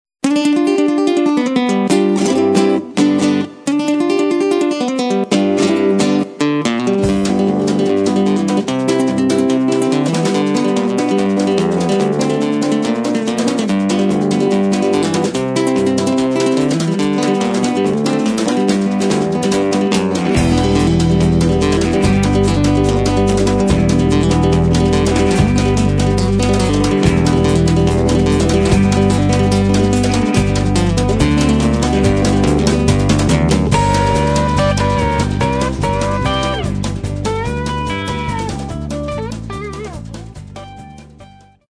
guitare (nylon & électrique)
Electric Guitar
Keyboards
Drums and percussions
Bass